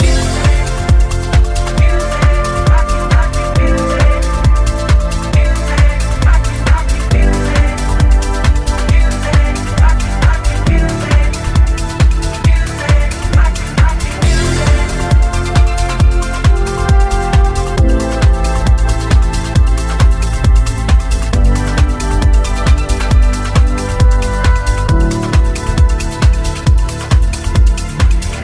Genere: house, chill house,remix